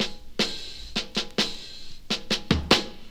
Empty Fill.wav